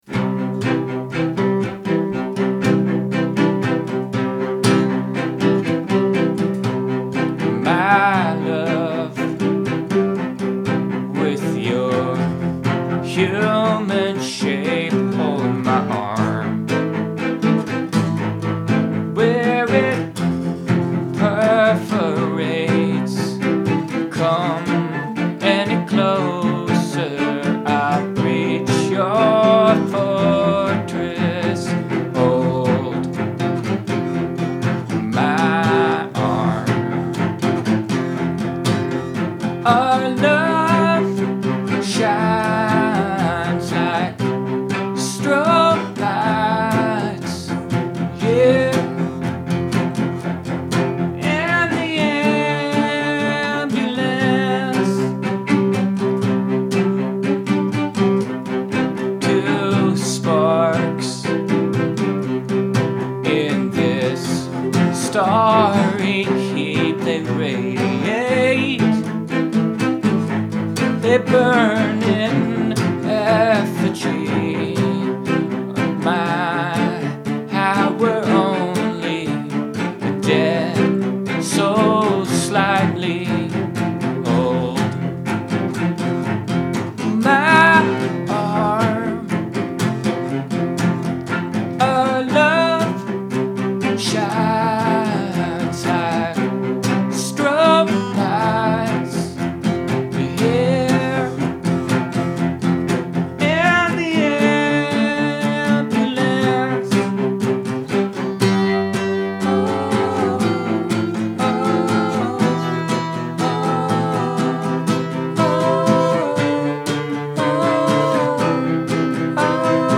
It's in C major.
3. that said, given the context, i hear the "oh-oh"s in the bridge like a siren (possibly even getting higher in pitch as it gets nearer).
4. this arrangement is dynamic and moving. the voices of the instruments have great character, even on this casual recording. it's great how you can hear when one or the other of you really starts digging into the instrument.
5. the the shape of the melody is compelling. good highs and lows. i really like the return of "hold my arm" at the end of the second line of each stanza. it's well-paced. you always take your time with a melody, you don't try to cram more motion or more notes than are needed. this is a good example of that. i wonder if that's a product of how you've said you write words and lyrics at the same time?